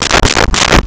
Door Knock 3x
Door Knock 3x.wav